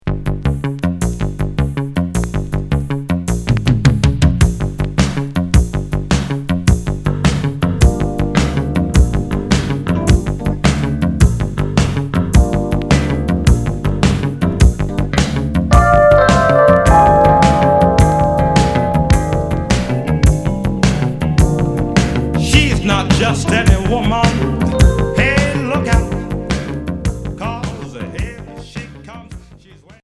New wave expérimentale Deuxième 45t